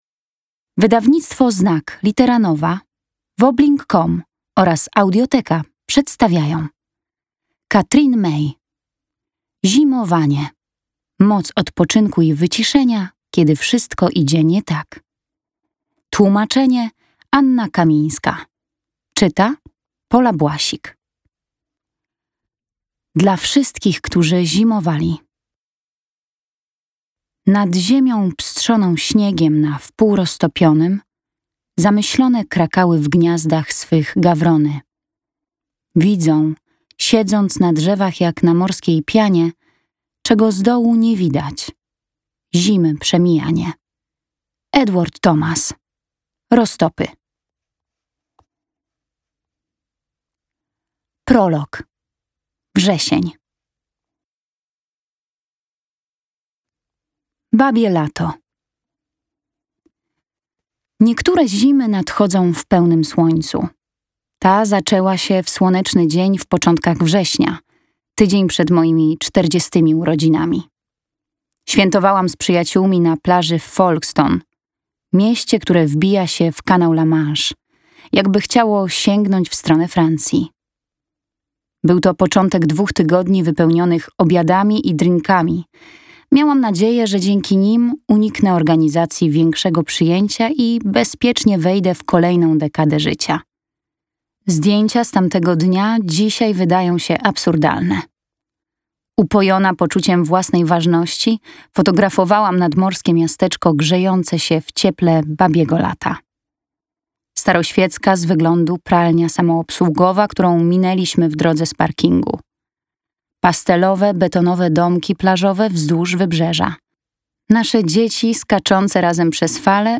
Moc odpoczynku i wyciszenia, kiedy wszystko idzie nie tak - May Katherine - audiobook - Legimi online